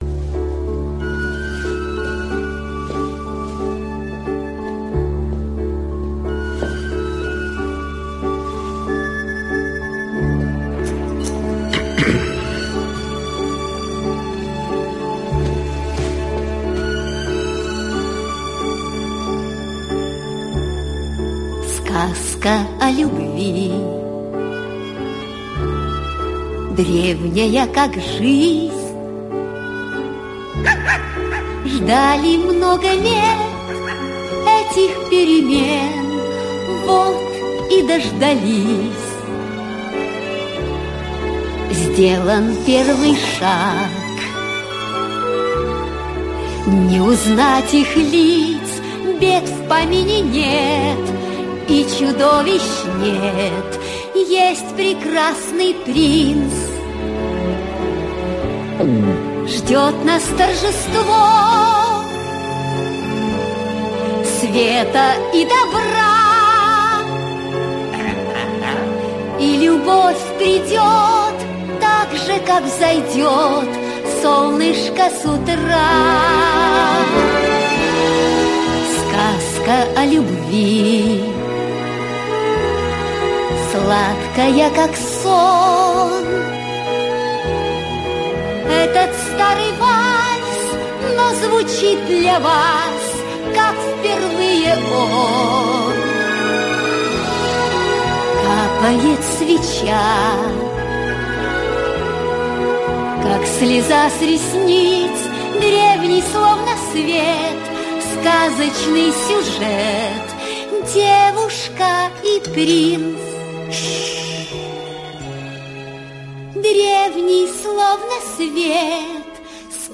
• Жанр: Детские песни
🎶 Детские песни / Песни из мультфильмов